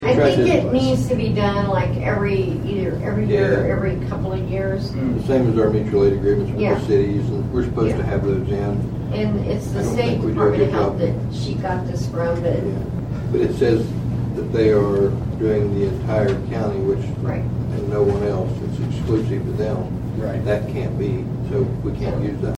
The Board of Nowata County Commissioners met for a regularly scheduled meeting at the Nowata County Annex on Monday morning.
District Three Commissioner Troy Friddle and County Clerk Kay Spurgeon discuss the agreement.